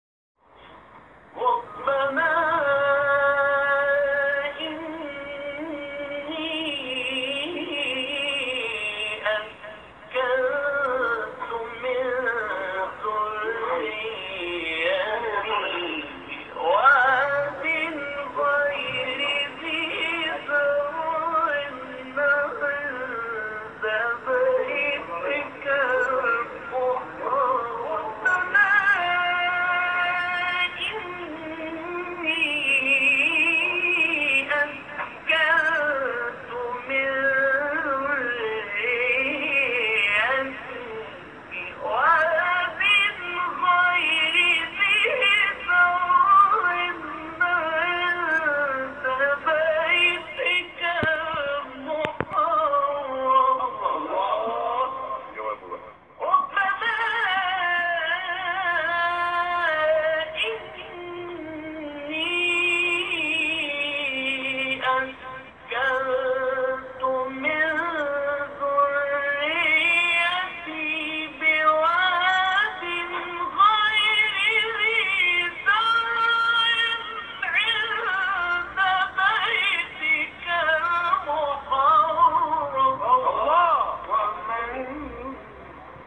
مقام-بیات.m4a